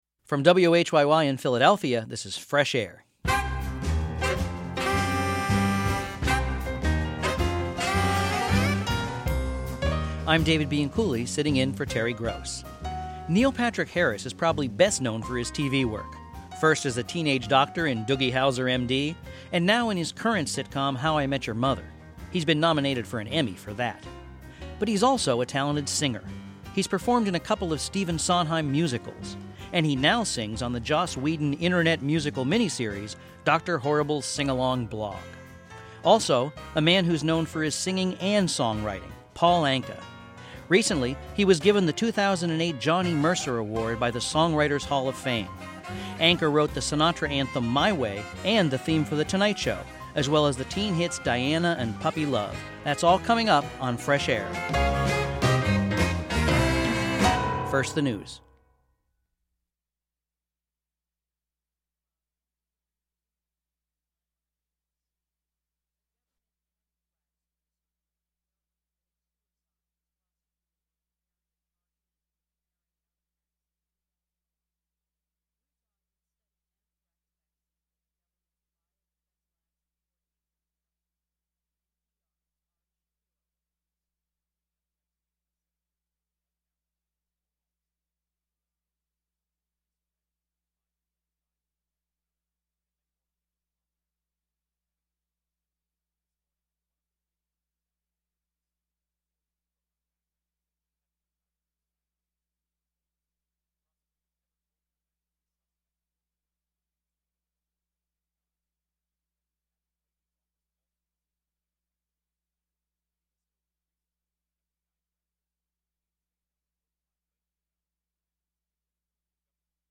An interview with Eva Marie Saint
Movie star Eva Marie Saint speaks about her career. Originally broadcast in 2000.